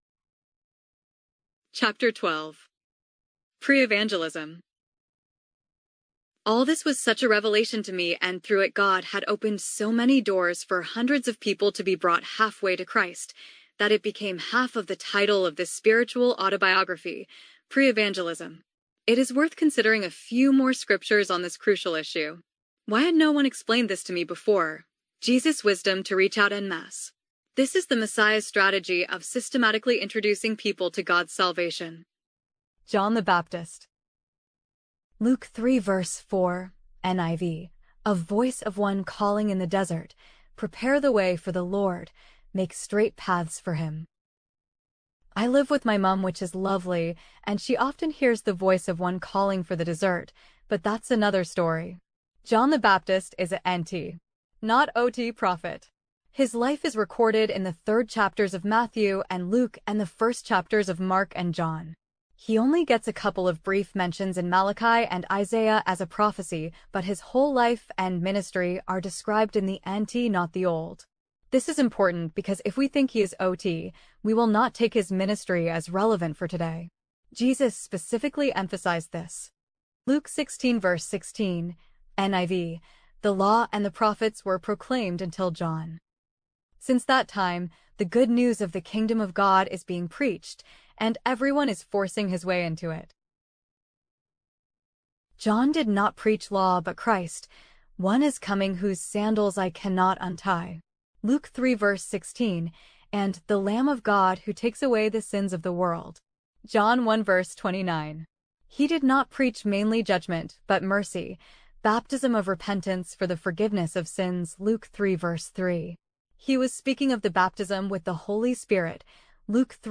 Audio book read by virtual reader, a totally life like sounding lady.